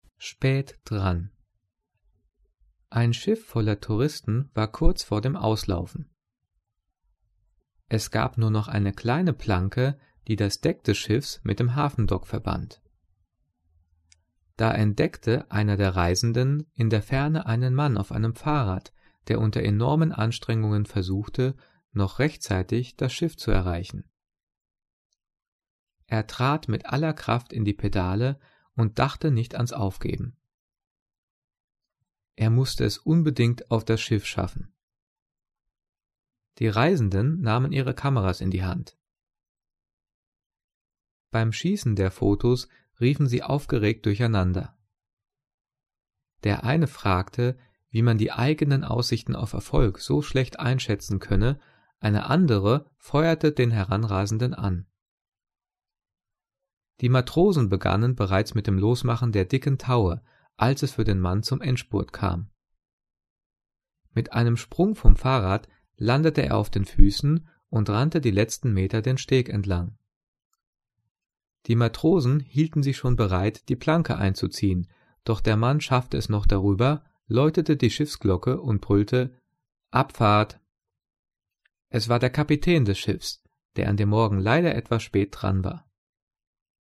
Gelesen:
gelesen-spaet-dran.mp3